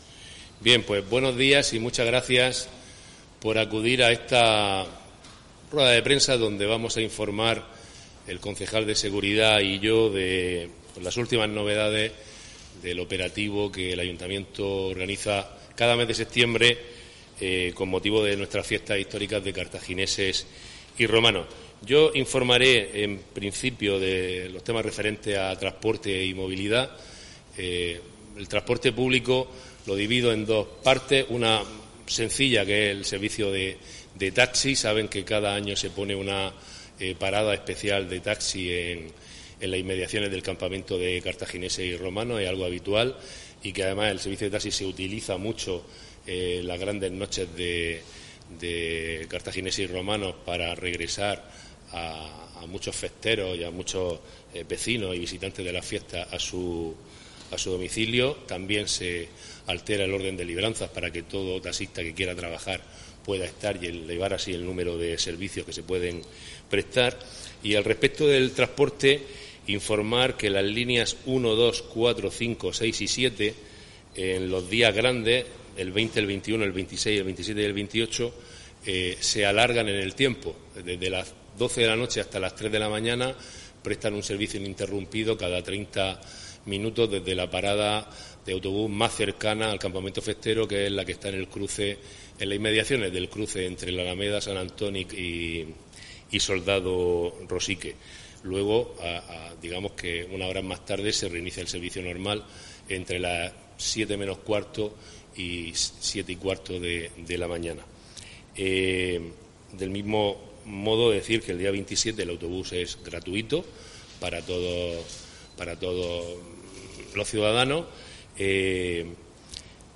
Enlace a Presentación del dispositivo de seguridad para Carthagineses y Romanos